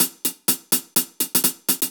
Index of /musicradar/ultimate-hihat-samples/125bpm
UHH_AcoustiHatA_125-03.wav